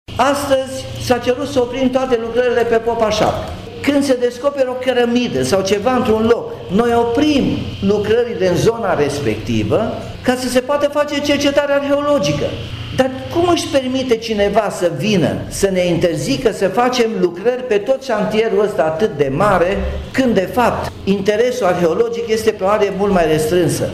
Lucrările de pe strada Popa Şapcă din Timişoara au fost oprite pentru descărcări arheologice. Anunţul a fost făcut de primarul Timişoarei, Nicolae Robu, care este revoltat de faptul că şantierul, de o importanţă deosebită pentru oraş, bate pasul pe loc.